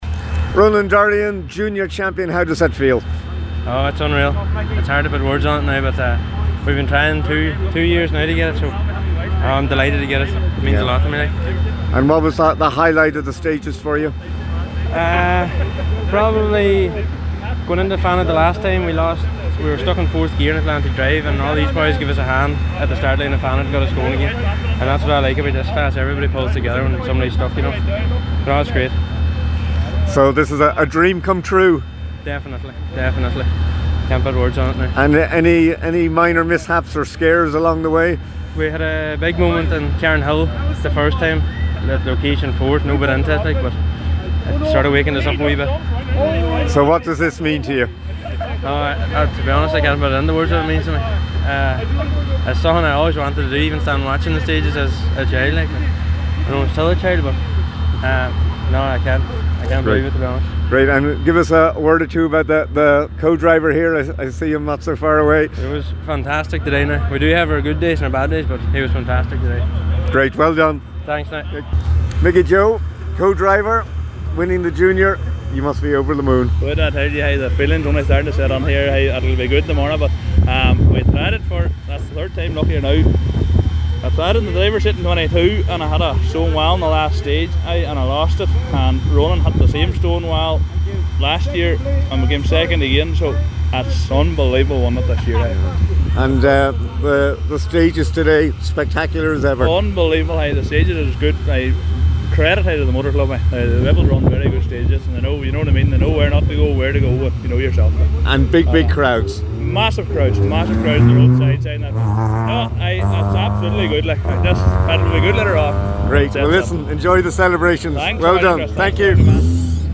Top competitors react to enthralling Donegal International Rally – Finish-line chats